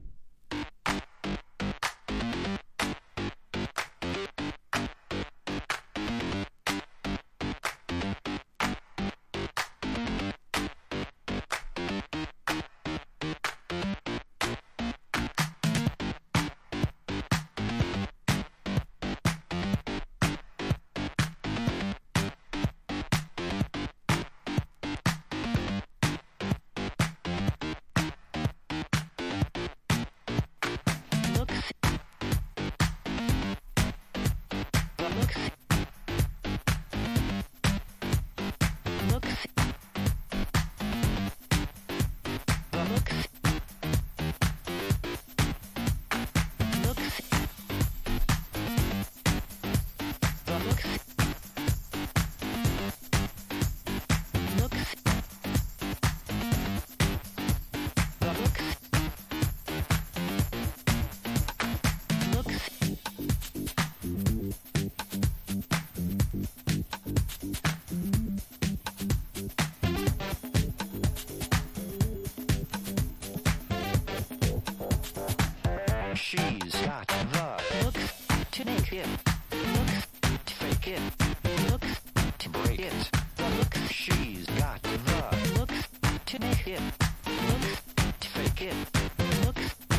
ハンド・クラップを多用した歯切れの良いブレイクビーツ・エレクトロ。